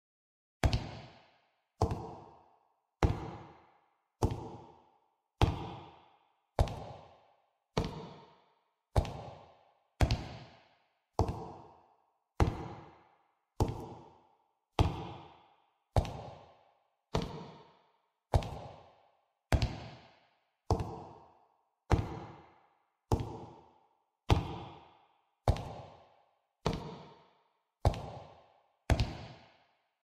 monster-footsteps.ogg